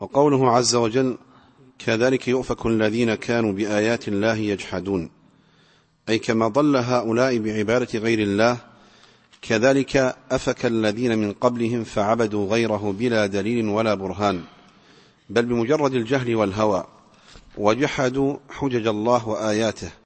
التفسير الصوتي [غافر / 63]